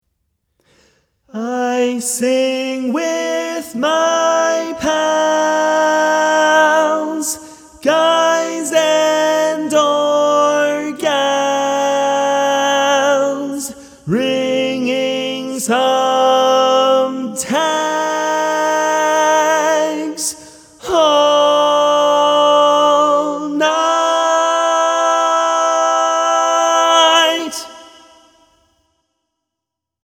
Key written in: C Major
Type: Barbershop